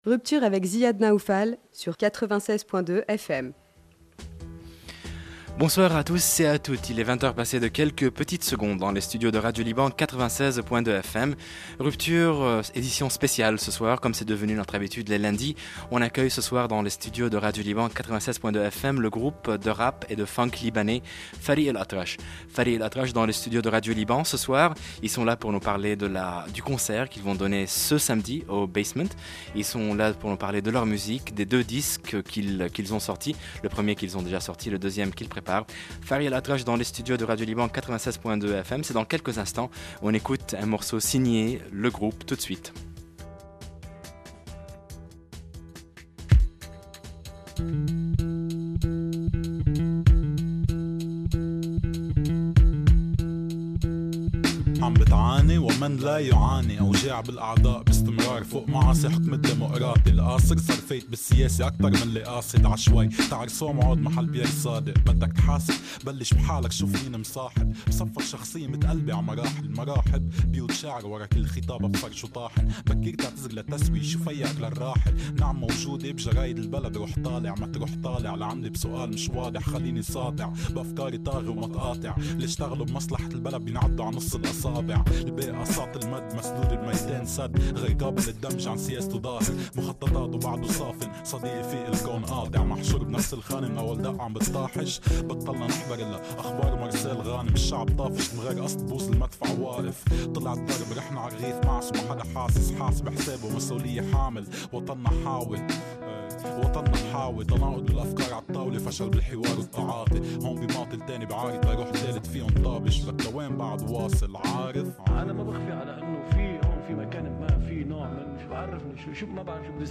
Lebanese hip hop band
bass
guitar and keyboards
holding it down on beatbox duties
playing three wonderfully laid-back tracks live
they offered a selection of their favorite tunes